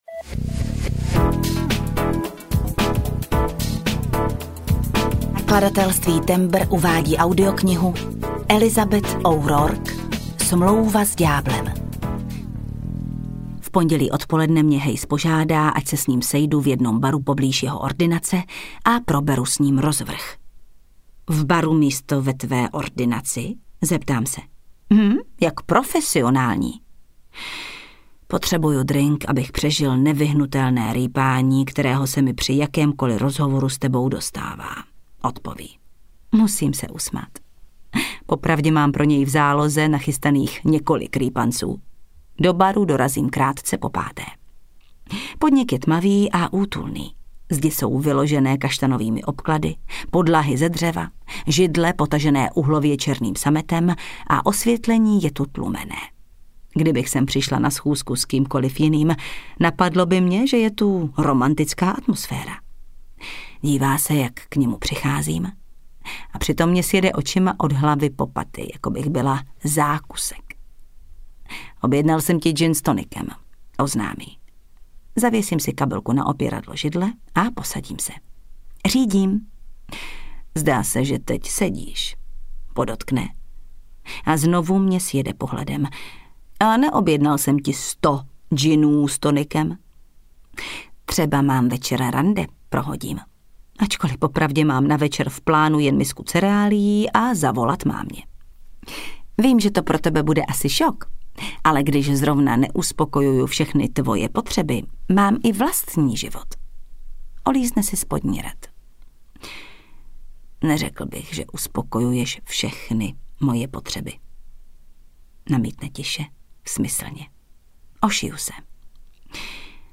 Smlouva s ďáblem audiokniha
Ukázka z knihy
• InterpretJana Stryková, Saša Rašilov